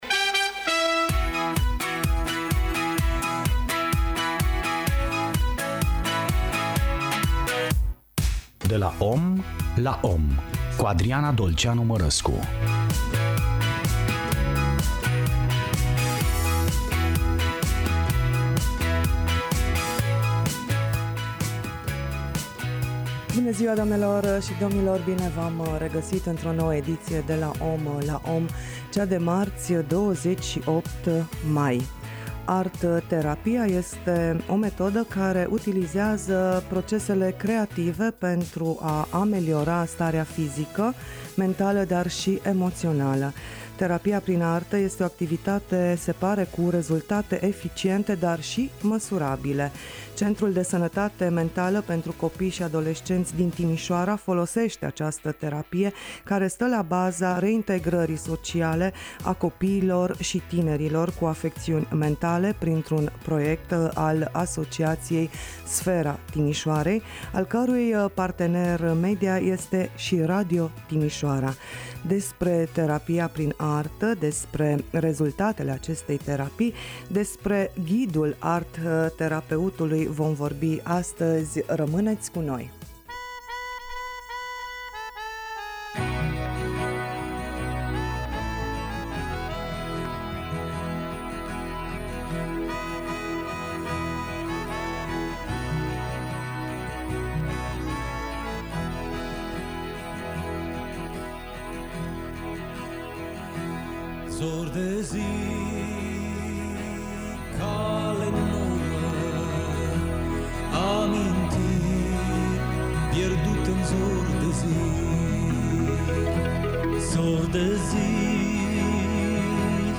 ArTerapia la Radio Timișoara – o discuție